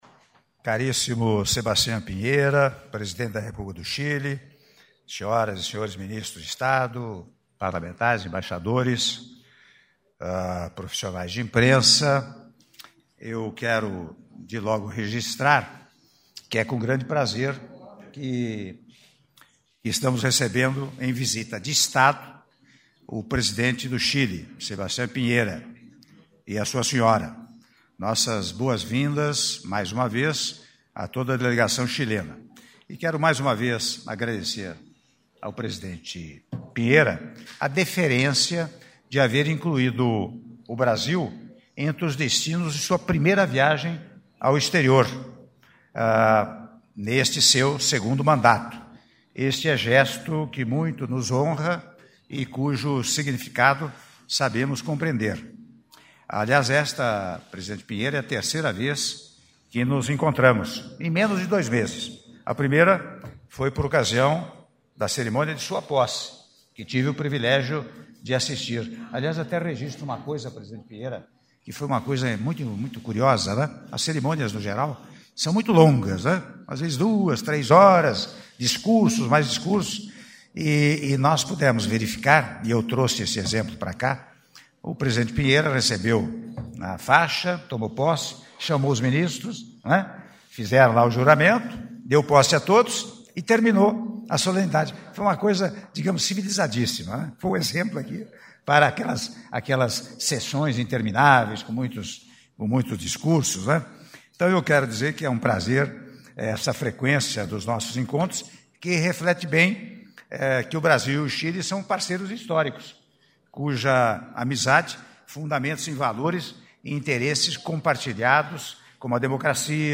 Áudio da declaração à imprensa do Presidente da República, Michel Temer, após cerimônia de assinatura de atos - Brasilia-DF - (07min13s)